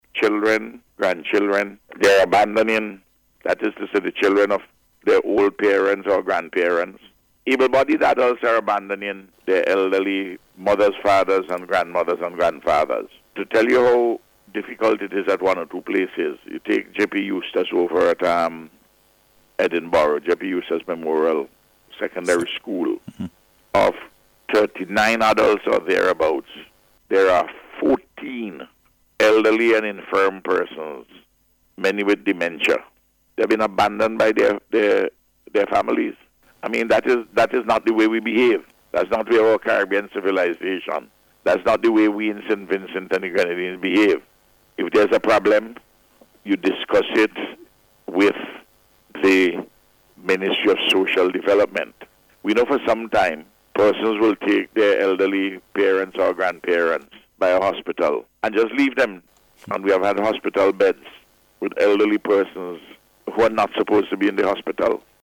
Speaking on NBC Radio this morning, Prime Minister Gonsalves highlighted several problems that are being faced at Emergency shelters.